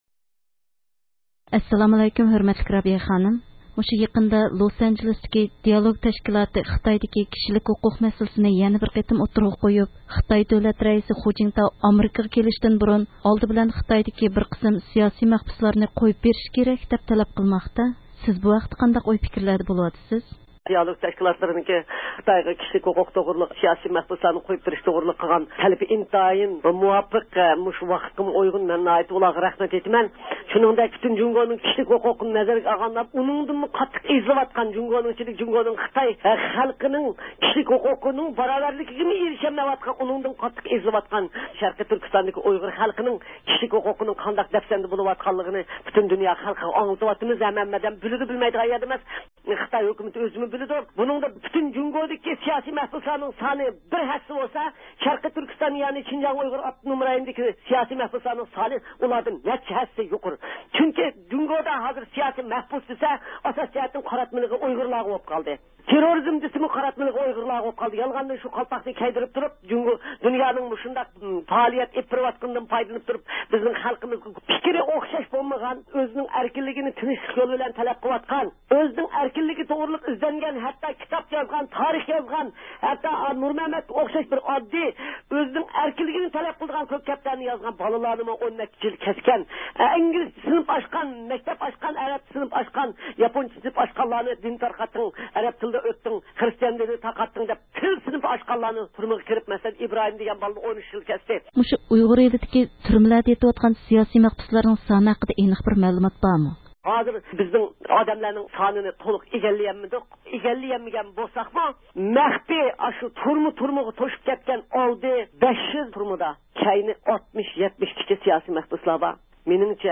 ئۇيغۇر ئېلىدىكى كىشىلىك ھوقۇق ۋە سىياسىي مەھبۇسلار ھەققىدە رابىيە قادىر خانىم بىلەن سۆھبەت – ئۇيغۇر مىللى ھەركىتى
رادىئومىزنىڭ زىيارىتىنى قوبۇل قىلغان خەلقئارا ئۇيغۇر كىشىلىك ھوقۇق ۋە دېموكراتىيە فوندىنىڭ رەئىسى رابىيە قادىر خانىم بۇ ھەقتە توختىلىپ، ئۆزىنىڭ خۇ جىنتاۋغا بولغان تەلەپلىرىنى ئوتتۇرىغا قويدى.